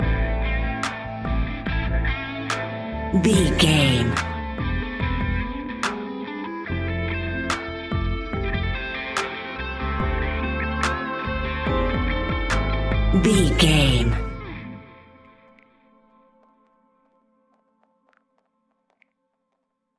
Epic / Action
Fast paced
In-crescendo
Uplifting
Ionian/Major
A♭
hip hop
instrumentals